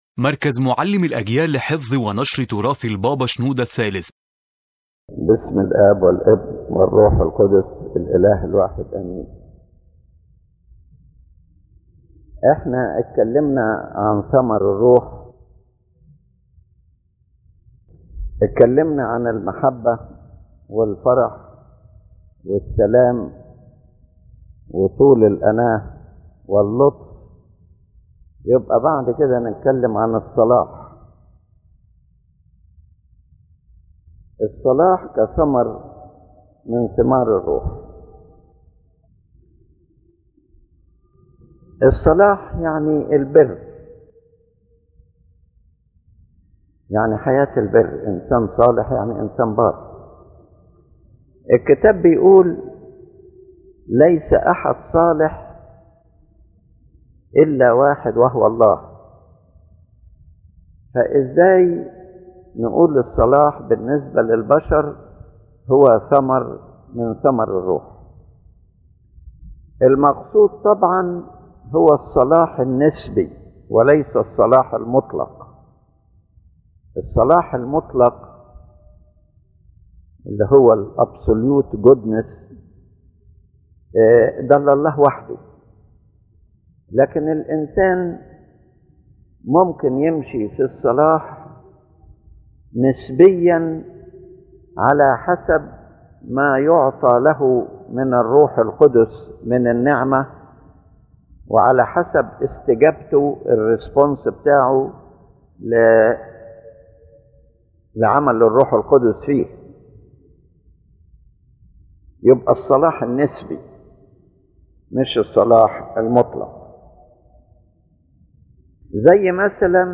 The lecture discusses goodness as a fruit of the Holy Spirit, explaining that true goodness is a relative goodness granted to humanity through the work of God’s grace and the human response to it, not the absolute goodness that belongs to God alone.